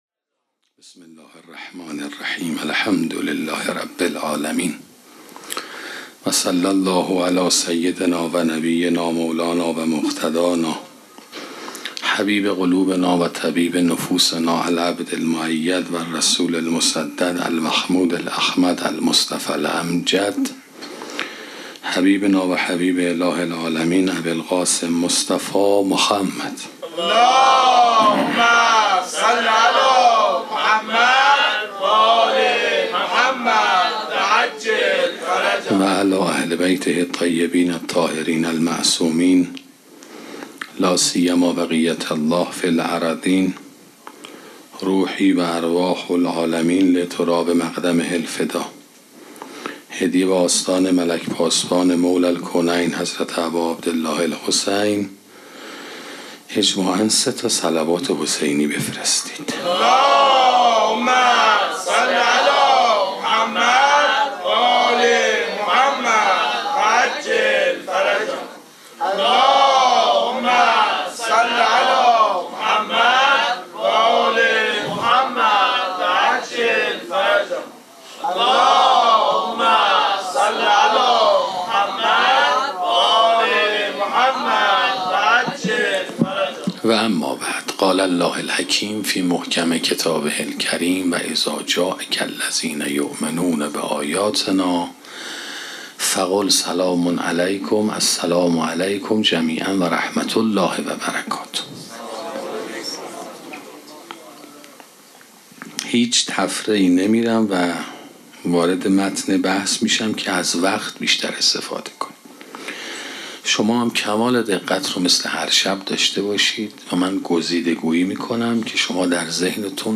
سخنرانی جایگاه معنوی مادر 5 - موسسه مودت